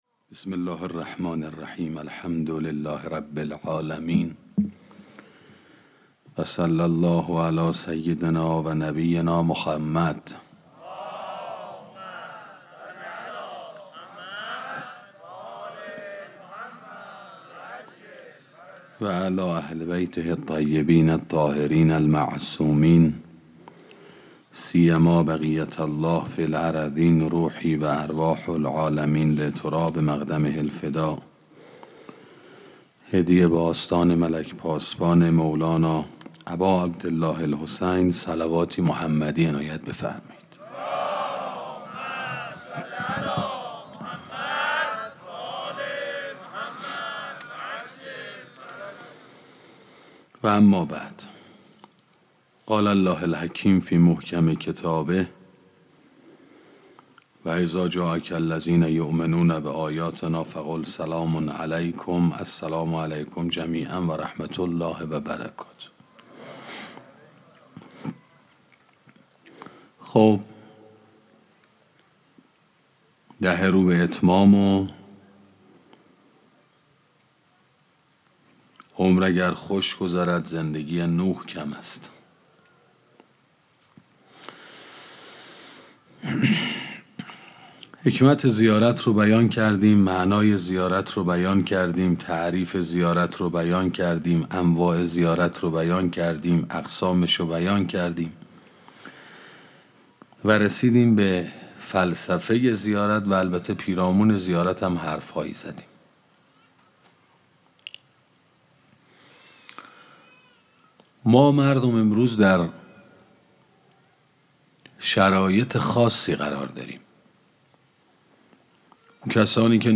سخنرانی شرح زیارت عاشورا 8